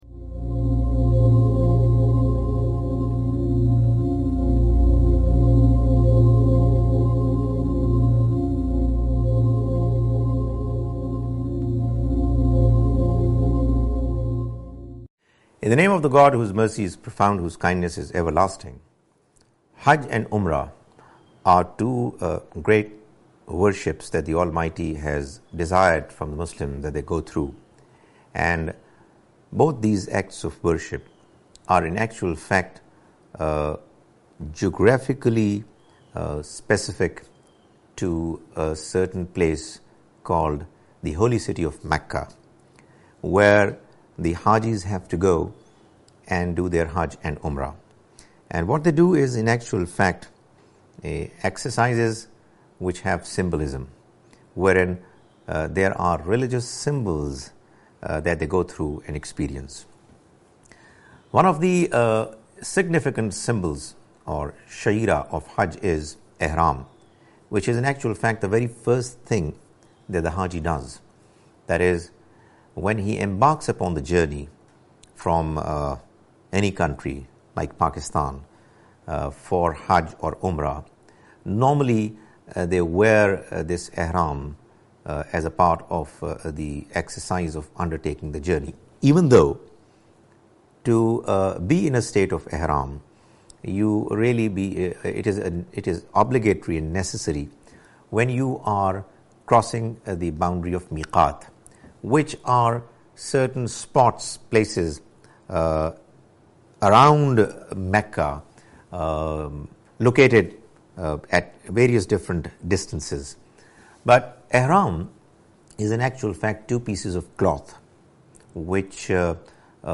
A short talk